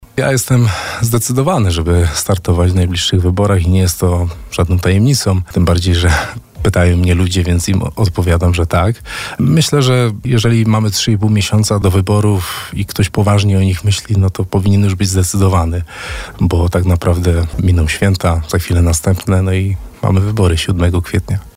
Jacek Kaliński, wójt gminy Kozy, zapowiedział na naszej antenie start w zbliżających się wyborach samorządowych.